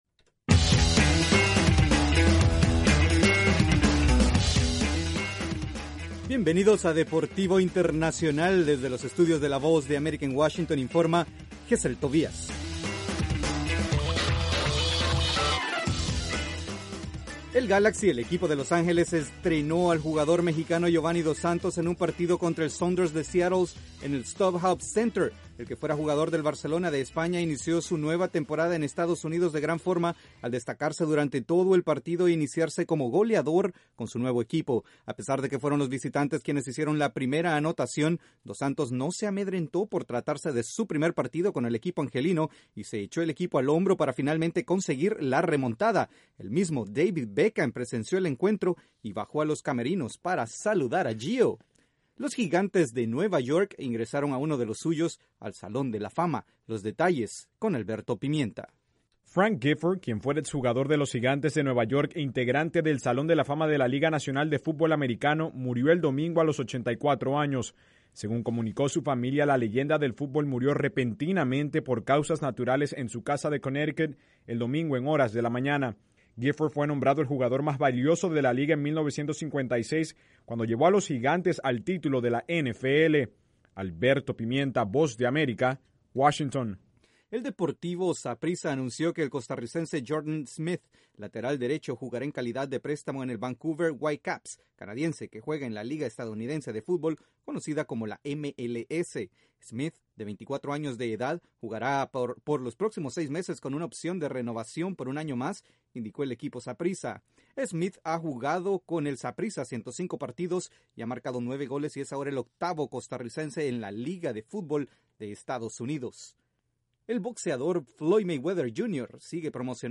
Escuche un resumen con lo más destacado de las noticias deportivas en este informe de 5:00 minutos